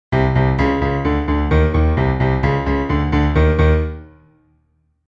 Short-dramatic-piano-musical-stinger-perfect-for-tension-or-cinematic-moments.mp3